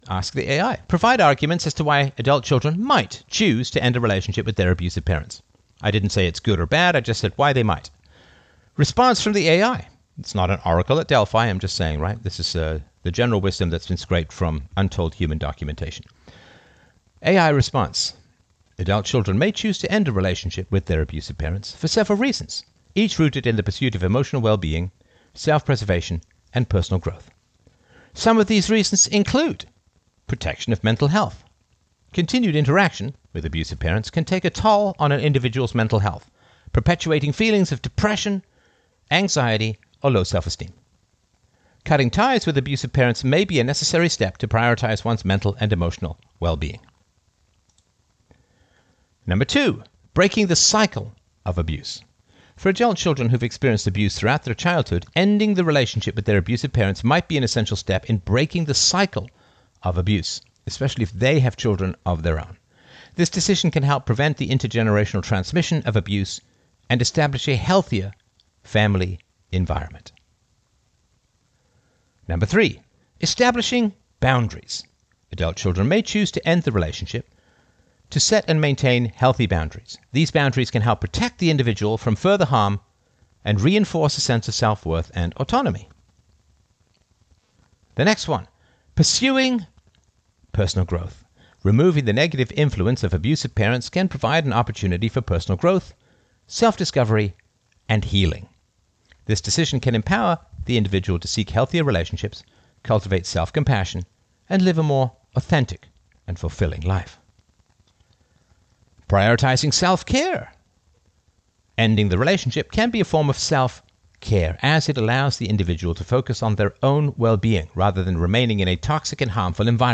Philosopher Stefan Molyneux queries artificial intelligence on when it might be appropriate to detach from abusive relationships.